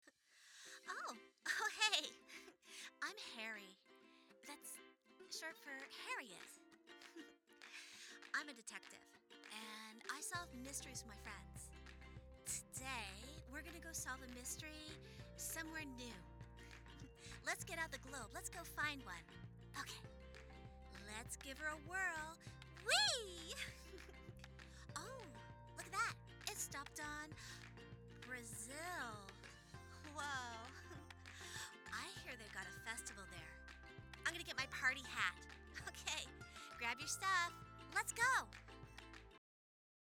Child Voice Demos
Kid Adventurer Detective Sample
Words that describe my voice are Genuine, Warm, Friendly.